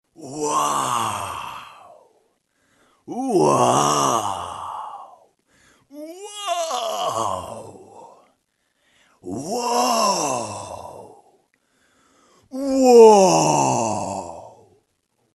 Звуки восхищения
На этой странице собраны звуки восхищения — яркие эмоциональные возгласы, восторженные восклицания и другие проявления удивления.
Ну ваще класс ВАУ несколько раз